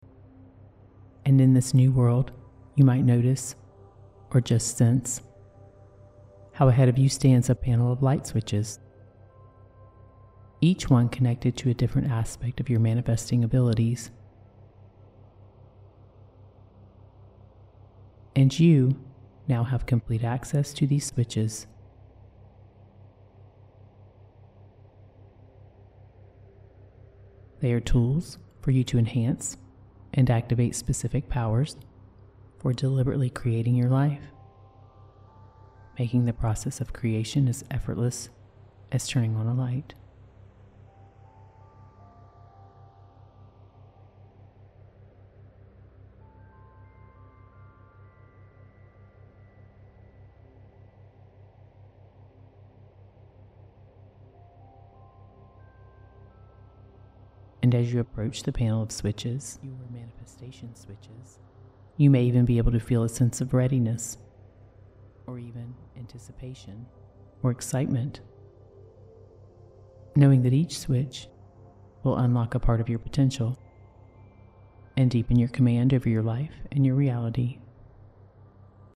With theta wave integration and an affirmation loop that carries you through the night, this session becomes a powerful tool for reprogramming your mind, ensuring deep absorption of each suggestion.
3D Binaural Sound – Engage with a full 3D soundscape that enhances the depth and impact of each hypnotic suggestion, creating a fully immersive experience.
Theta Waves – Connect with deeper meditative states, optimizing your brain for absorption and alignment with your desires.
Affirmation Loop – After the main hypnosis, an all-night affirmation loop subtly reinforces the hypnotic suggestions, embedding each intention into your subconscious mind for maximum impact.